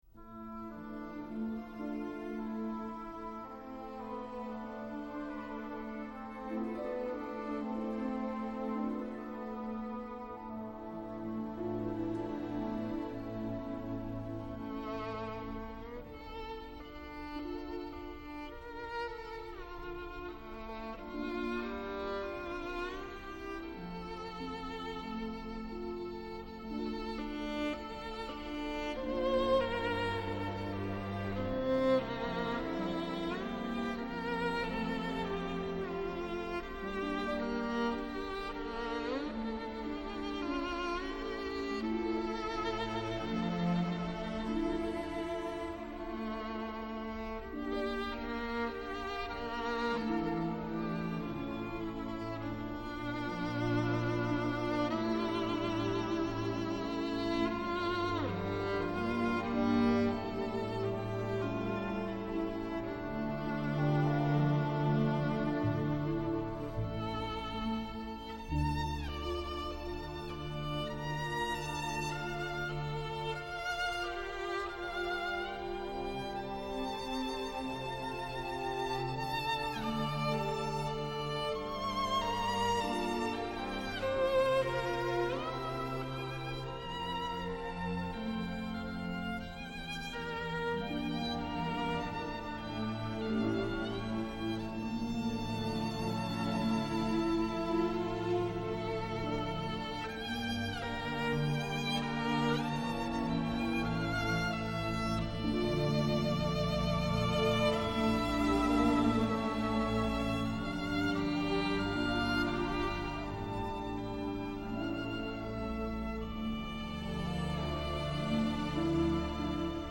Στην σημερινή εκπομπή καλεσμένοι :